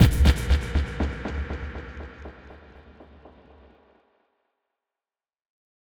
Impact 16.wav